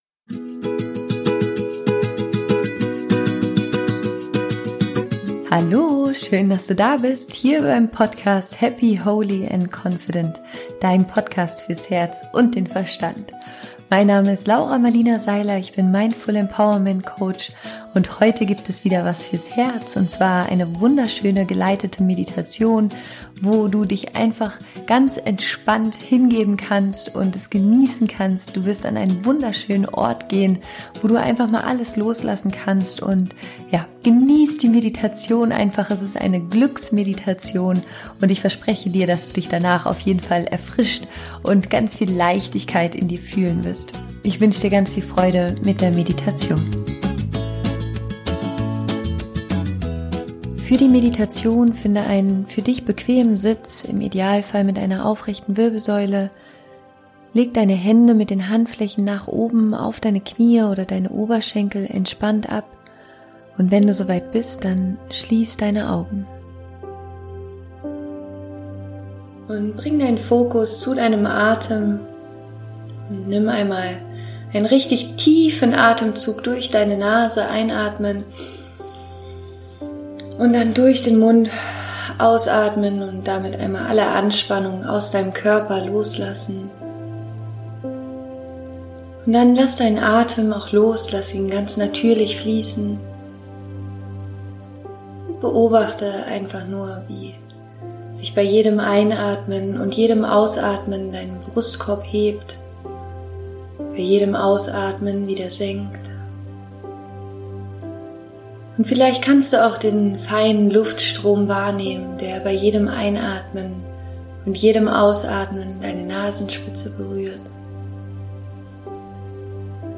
Finde für die Meditation einen aufrechten und bequemen Sitz, schließe deine Augen und lass dich von mir durch die Meditation führen.